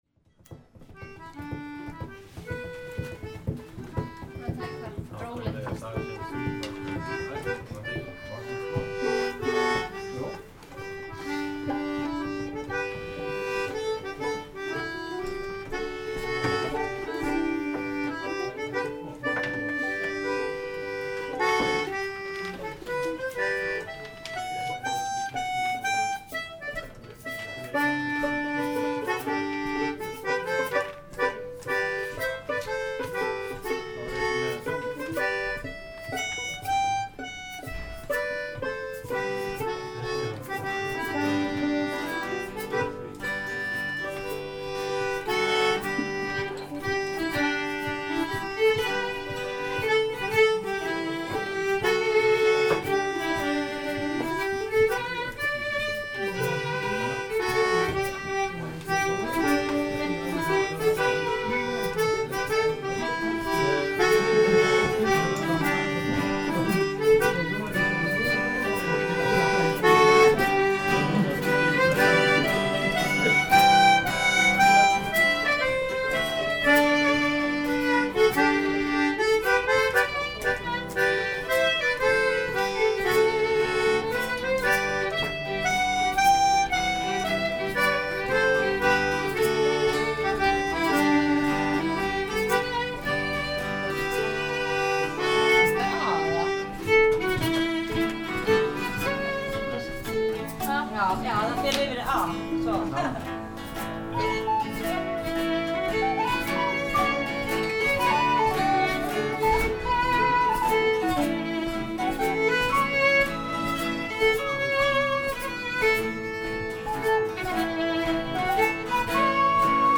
Open session
Á fimmtudögum mætir fólk með hljóðfæri á veitingahúsið Highlander að Lækjargötu 10 til að spilað af hjartans list, þá helst keltneska tónlist.
Þá sýna sig líka byrjendur og þaulvanir snillingar.
Voru þar mættir fimm hljóðfæraleikarar.
Tekið var upp á Olympus LS10 í 48Khz/24bit. á MMaudio Binaural hljóðnema.